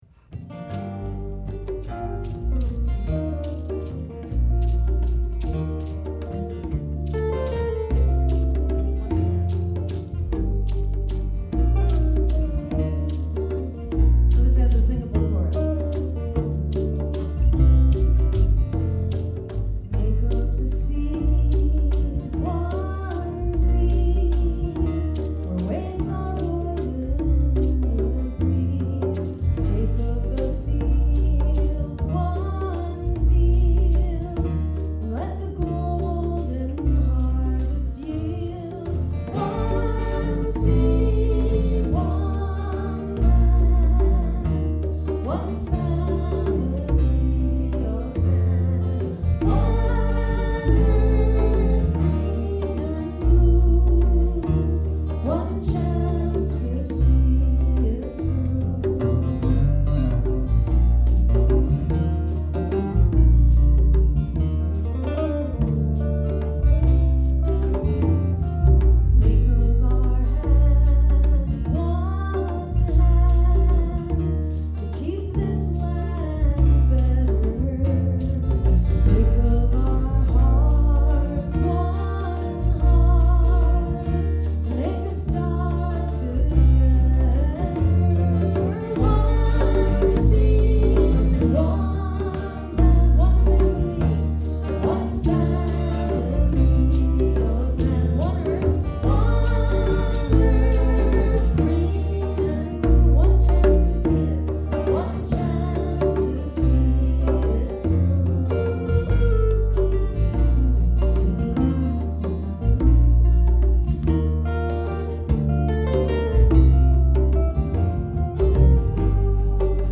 Recorded at the Green-Rainbow Convention, Leominster, MA 11/23/08 Jill Stein - vocals, guitar, percussion
bass
djembe